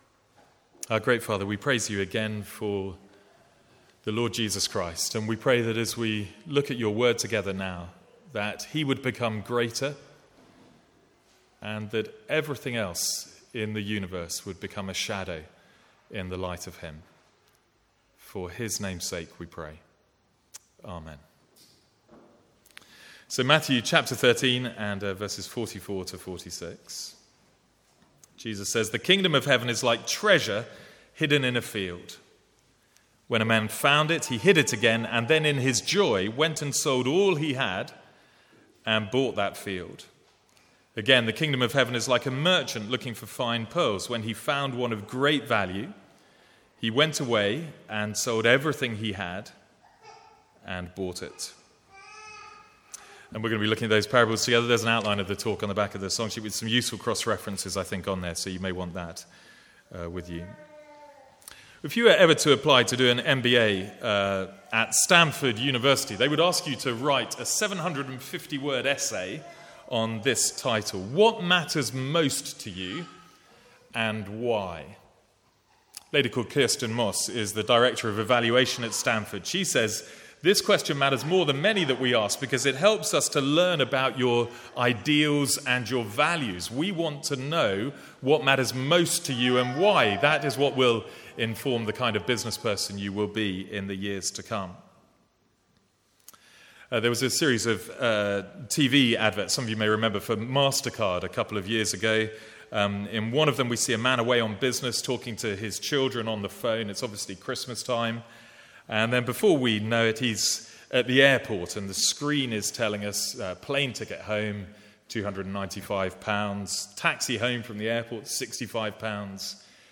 Sermons | St Andrews Free Church
From the Sunday morning series in Matthew.